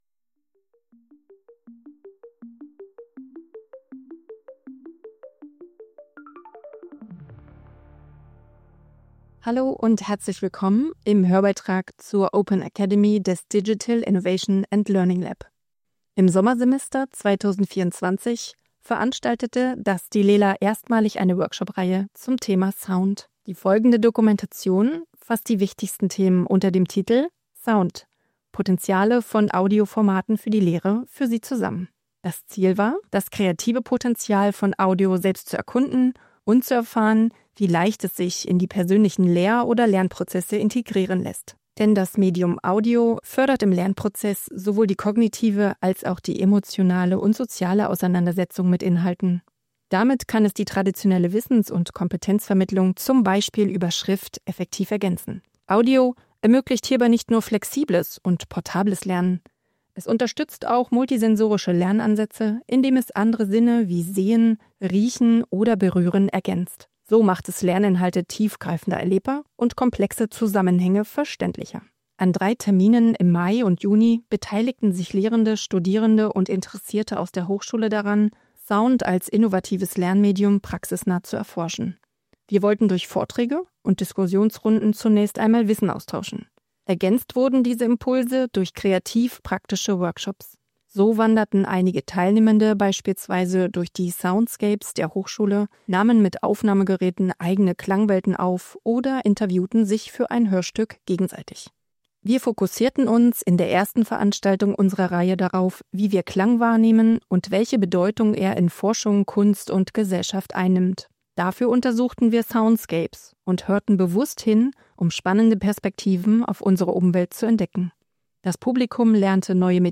Beschreibung vor 3 Monaten Audio-Mitschnitt der Live-Veranstaltung vom 29. Mai 2024 Wie klingt unsere Umwelt und was erzählt sie uns?